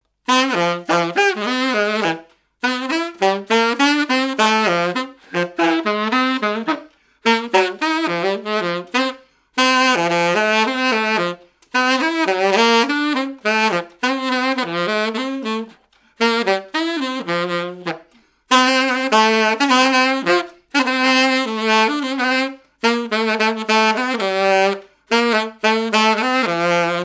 Quadrille
danse : quadrille : galop
activités et répertoire d'un musicien de noces et de bals
Pièce musicale inédite